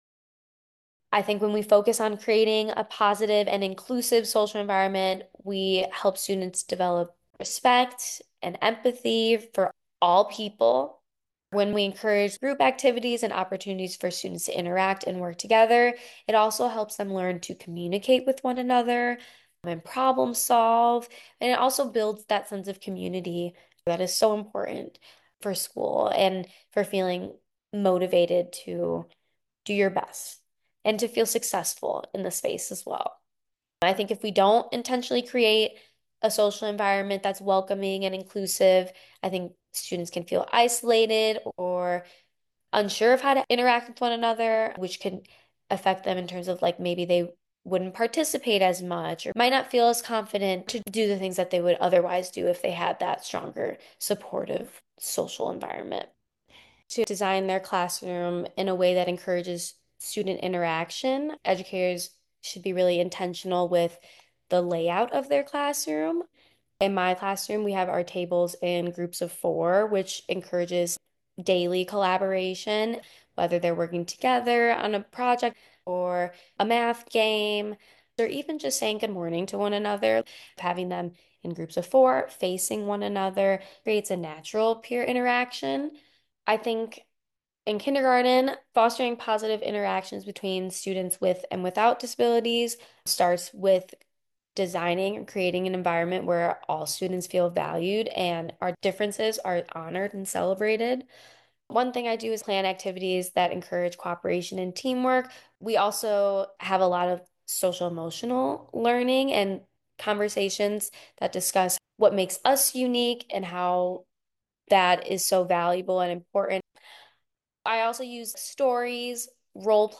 Kindergarten teacher